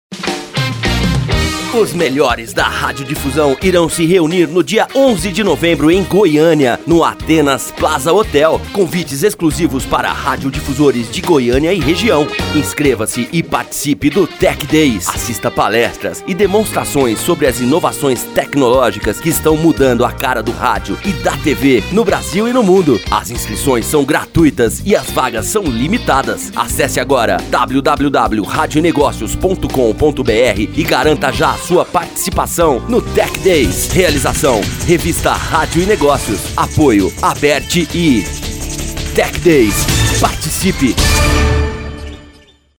SPOT_TECHDAYS_GOIANIA2016.mp3